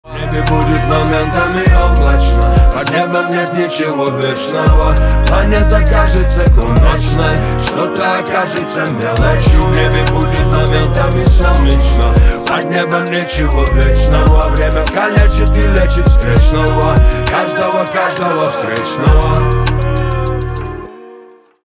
русский рэп
басы